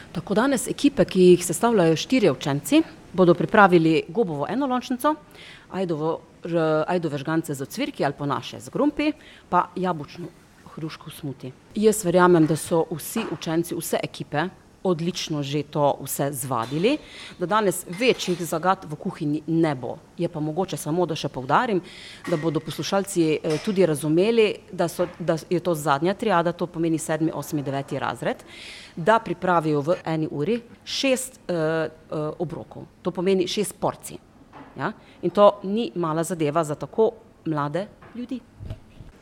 V Slovenj Gradcu danes poteka prvo od sedmih regijskih tekmovanj Zlata kuhalnica, priljubljeno tekmovanje osnovnošolskih kuharskih talentov.